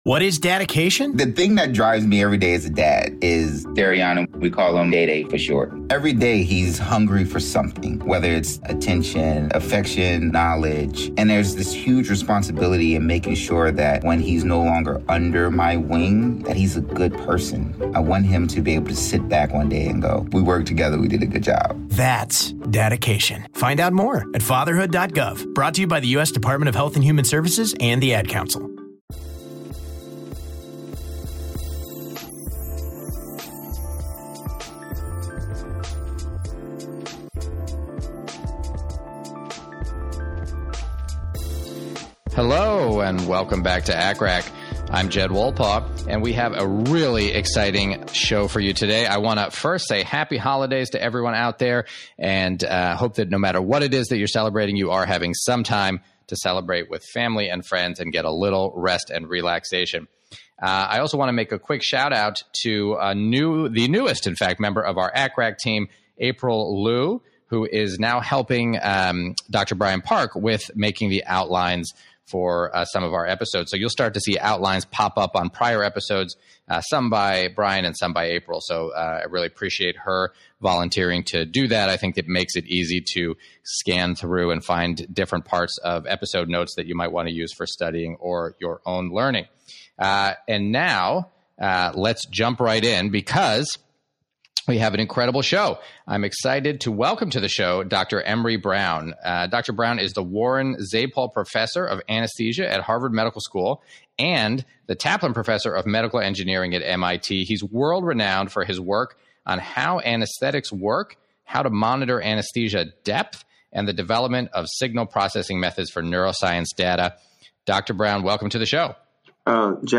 In this 154th episode I welcome Dr. Emery Brown to the show to discuss how we monitor the depth of anesthesia.